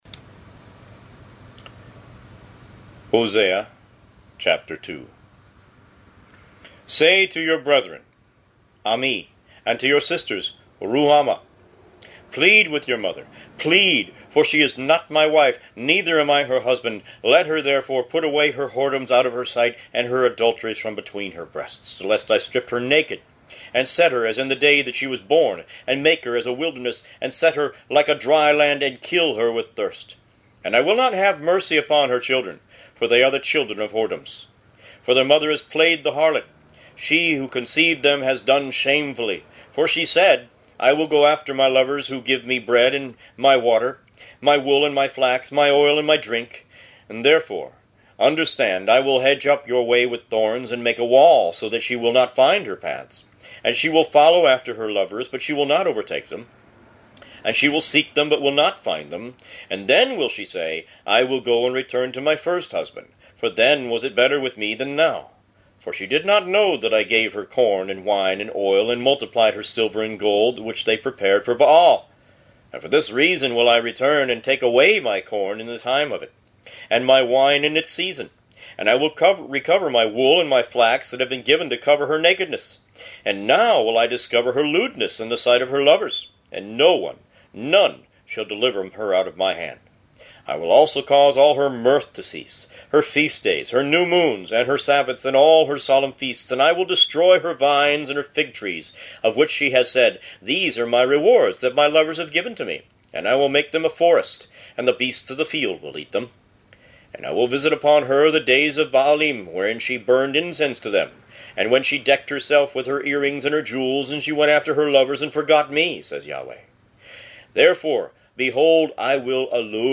Root > BOOKS > Biblical (Books) > Audio Bibles > Tanakh - Jewish Bible - Audiobook > 28 Hosea